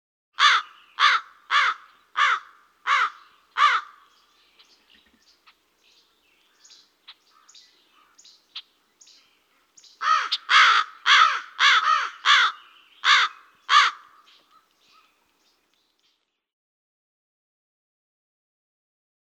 crow.mp3